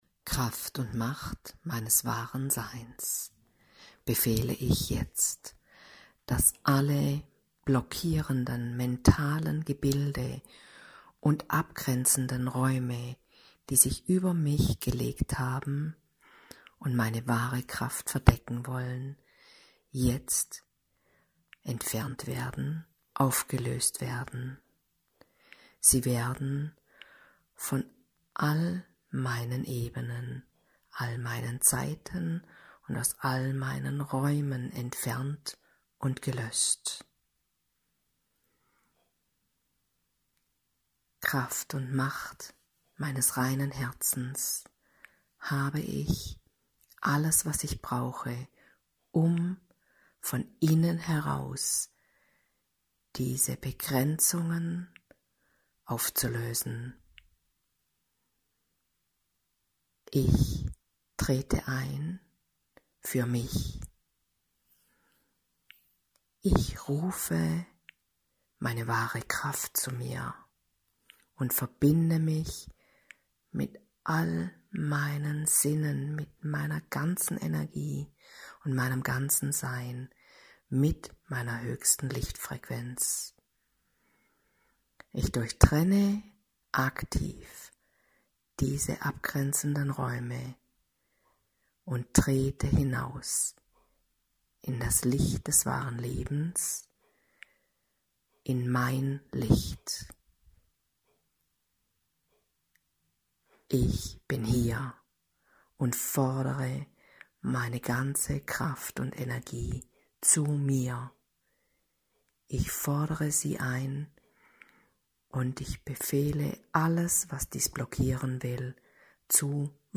Hier eine wunderbare und einfache und wirkmächtige Übung für dich + gesprochene Kraftausrichtung per mp3